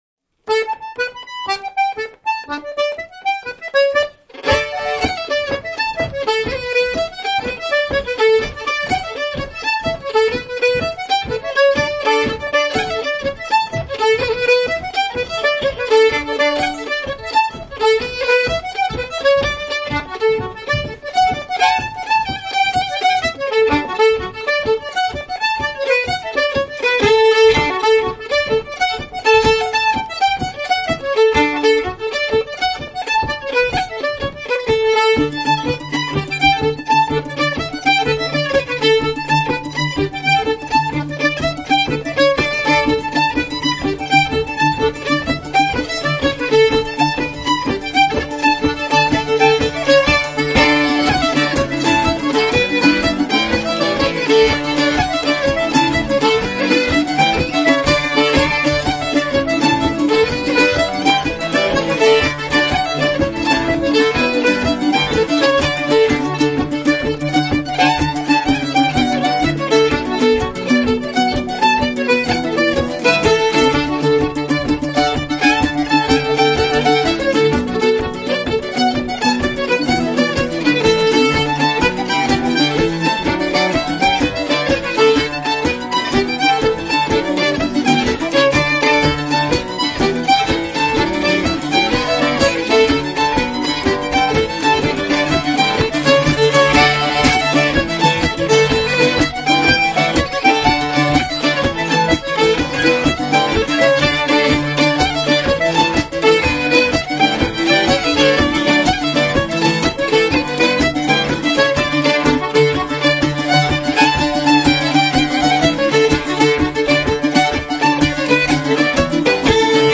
Below are links to mp3s of the FSC Country Dancing music as heard and used on camp.